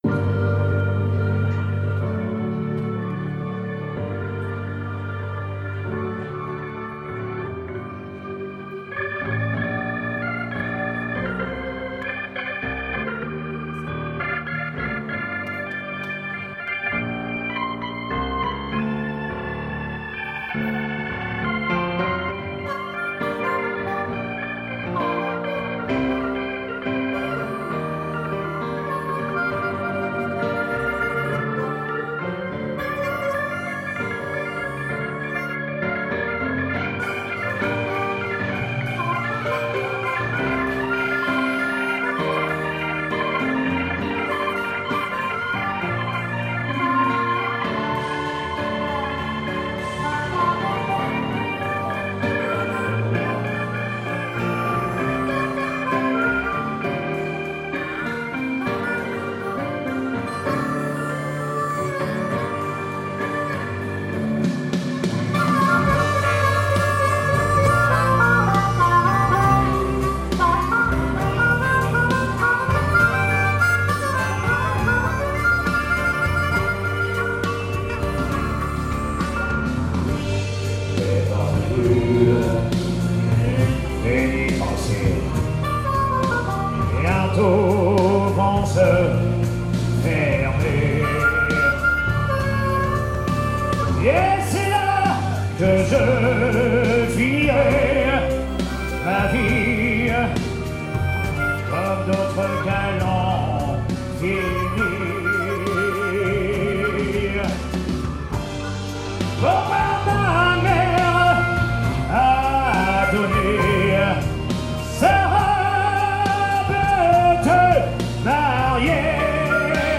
SOIREES BLUES-ROCK RETROSPECTIVE